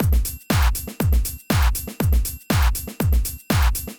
Drumloop 120bpm 04-A.wav